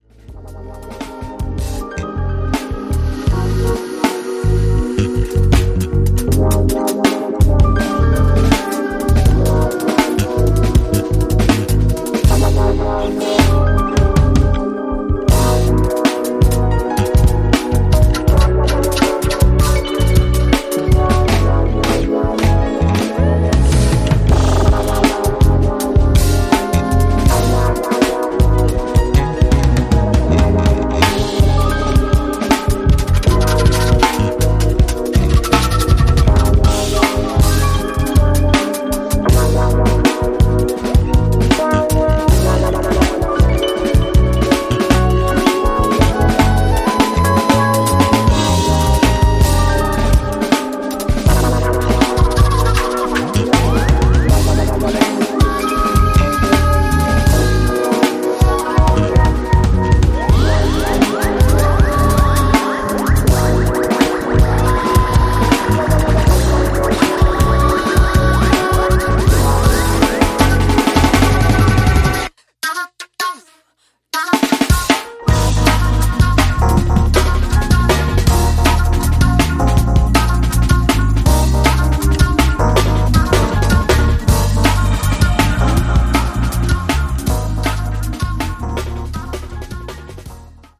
Dubbed 'nu-jazz', 'jazztronica' and 'jazz house music'
Music made by human hands.
Deep house, Soulful house